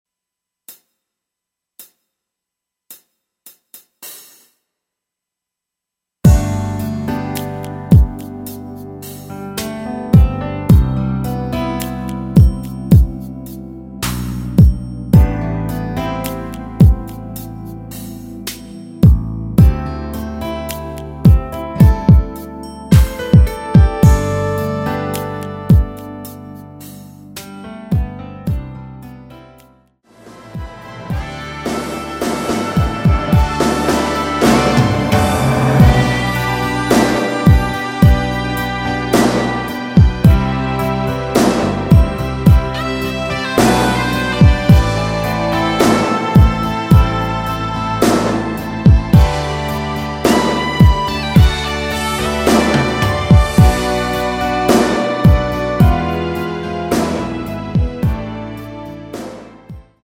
MR입니다.
앞부분30초, 뒷부분30초씩 편집해서 올려 드리고 있습니다.
중간에 음이 끈어지고 다시 나오는 이유는